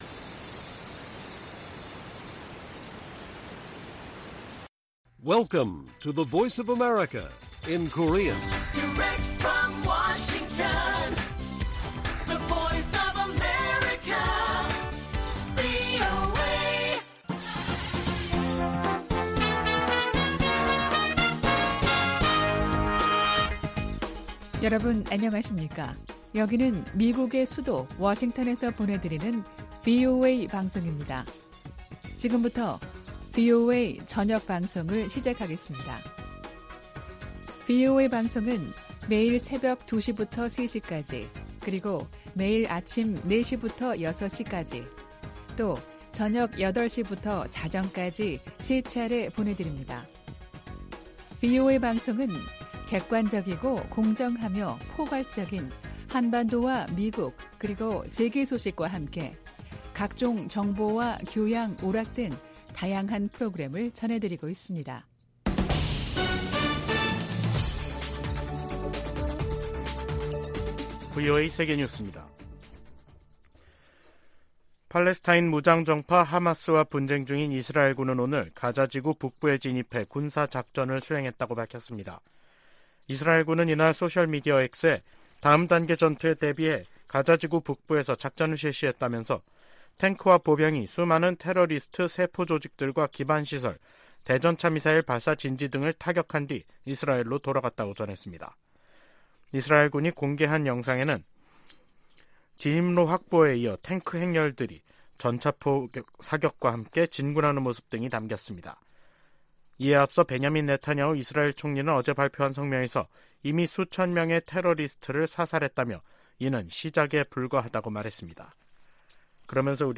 VOA 한국어 간판 뉴스 프로그램 '뉴스 투데이', 2023년 10월 26일 1부 방송입니다. 미국, 한국, 일본 세 나라 외교장관들이 북한과 러시아 간 불법 무기 거래를 규탄하는 공동성명을 발표했습니다. 북한은 유엔에서 정당한 우주 개발 권리를 주장하며 사실상 우주발사체 발사 시도를 계속하겠다는 뜻을 내비쳤습니다.